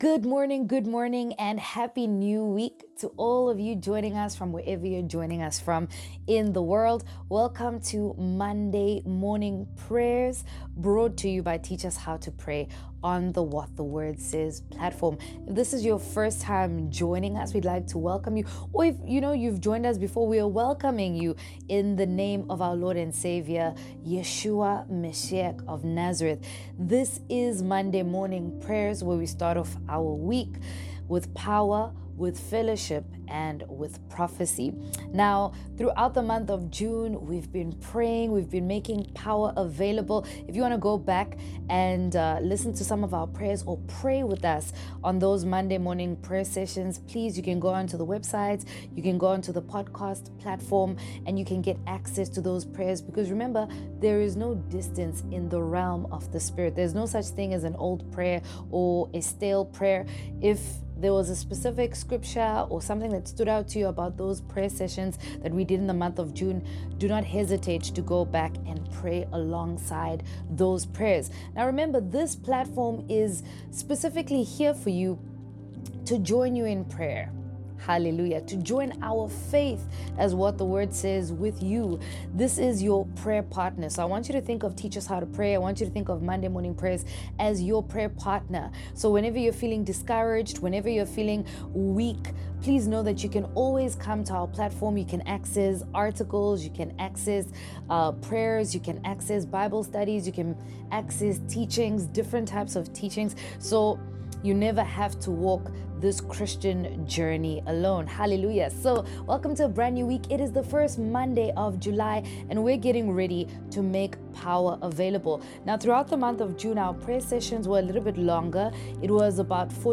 Monday Morning Prayers 5